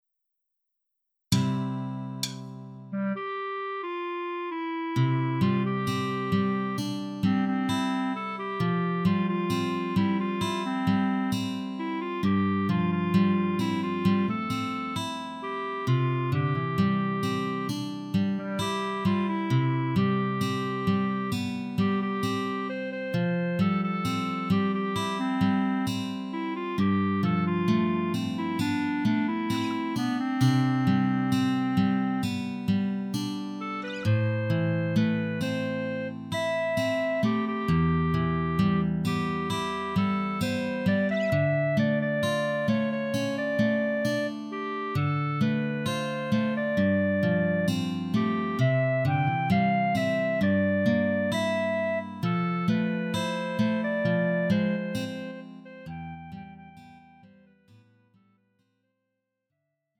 음정 원키 3:45
장르 가요 구분 Lite MR